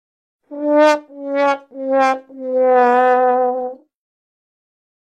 Sad Trombone